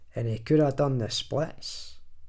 glaswegian_audio
glaswegian
scottish